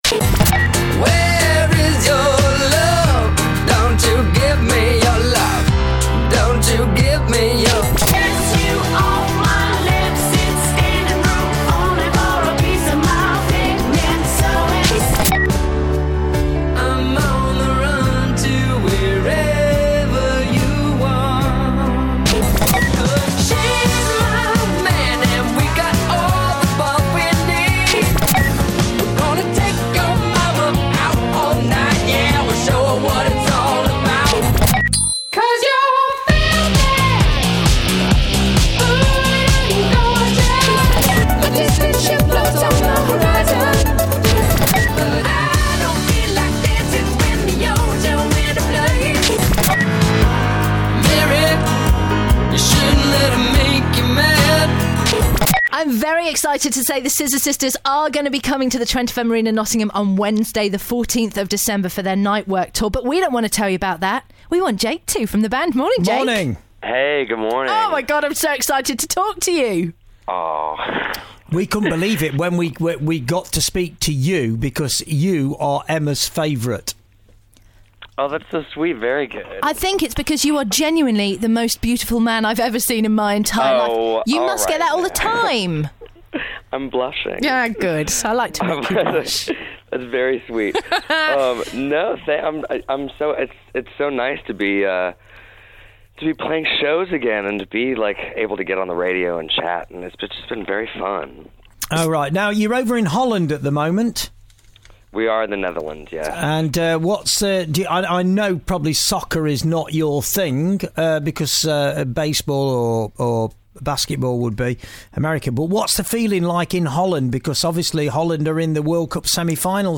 Check out what Jake Shears from the Scissor Sisters had to say when Trent FM called him!!